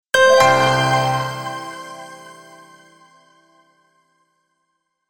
tune closing